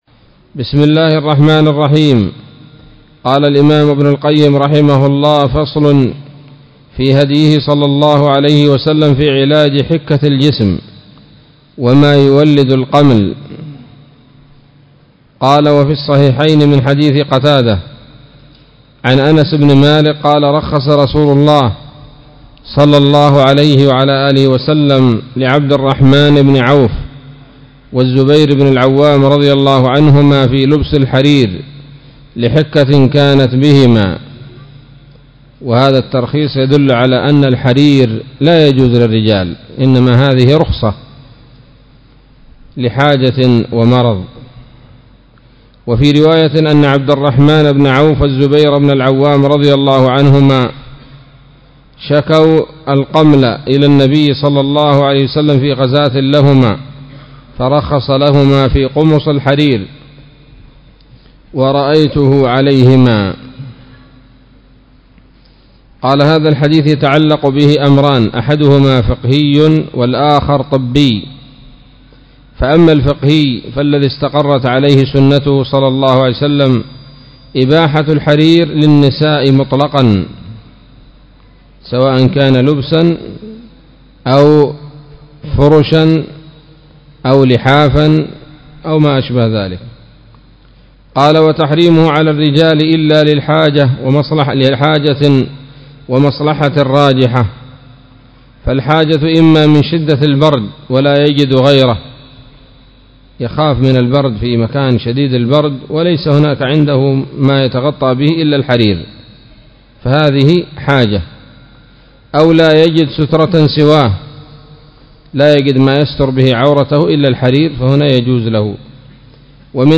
الدرس الحادي والعشرون من كتاب الطب النبوي لابن القيم